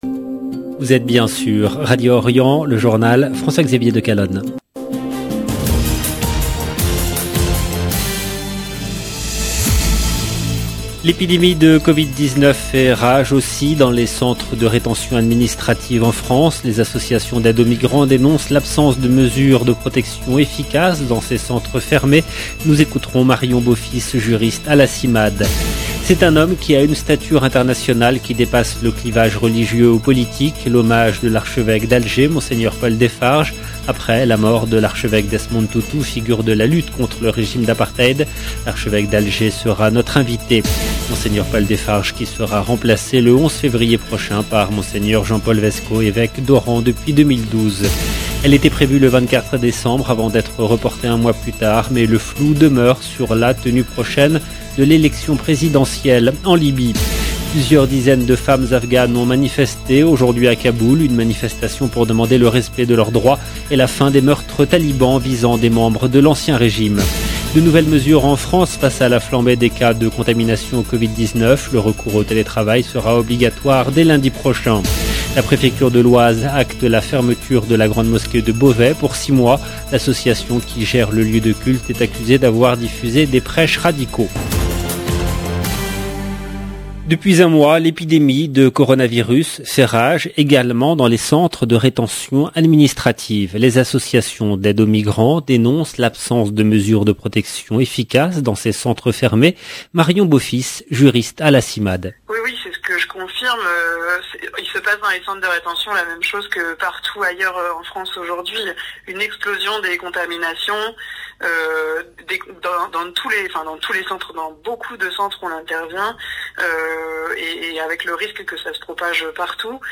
LB JOURNAL EN LANGUE FRANÇAISE
L'Archevêque d’Alger sera notre invité.